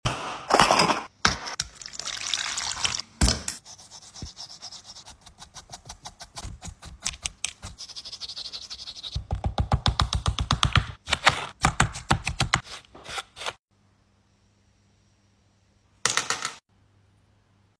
ASMR Underwater Diamond Mining!! sound effects free download